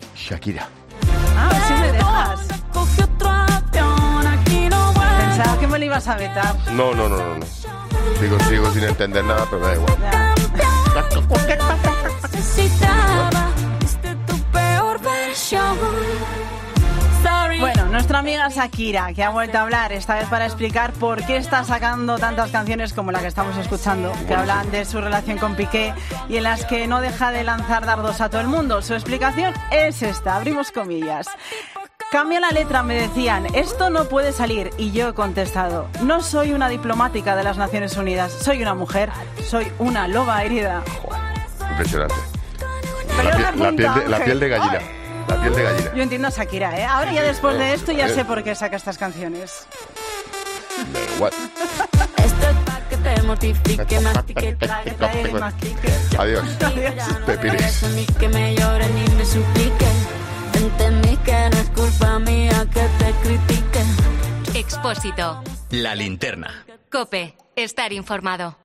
Expósito comenta los motivos de Shakira para continuar estrenando canciones con dardos a Piqué
Así, la reacción de Ángel Expósito no se ha hecho esperar, y ha sido de lo más rotunda: “Impresionante, la piel de gallina”, sentenciaba con mucha ironía.